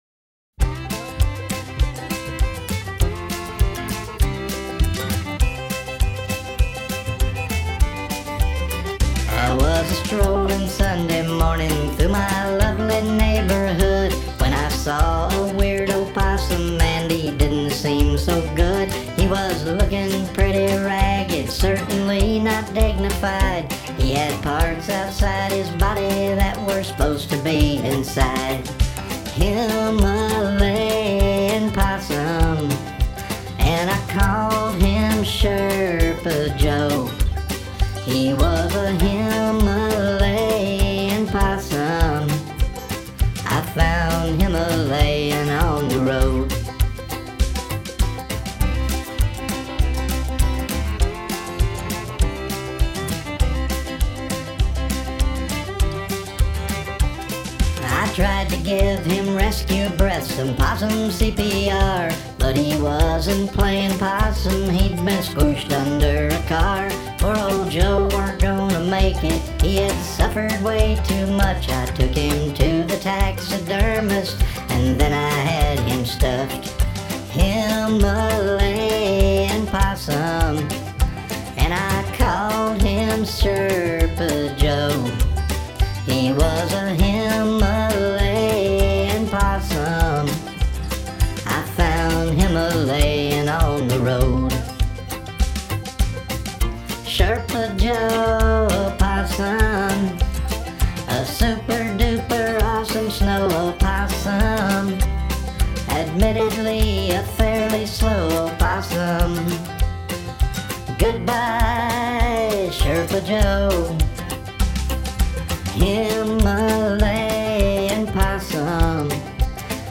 BYU Filk Club 21 February 2024